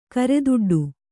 ♪ kareduḍḍu